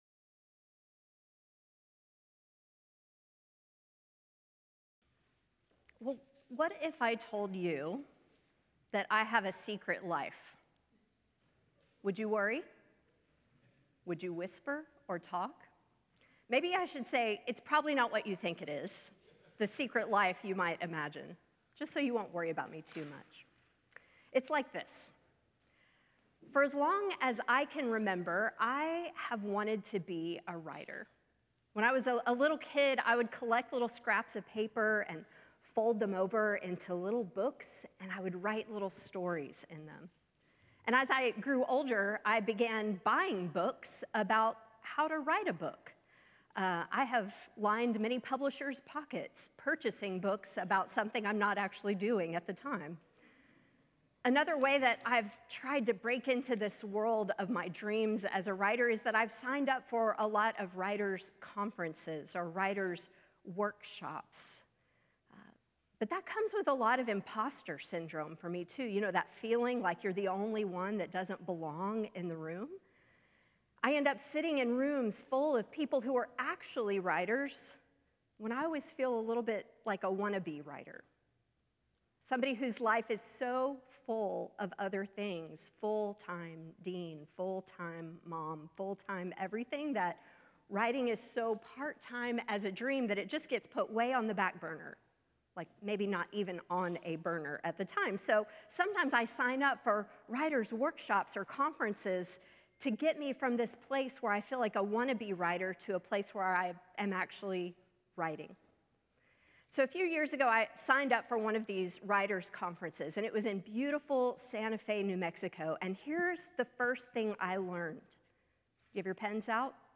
The following service took place on Wednesday, February 12, 2025.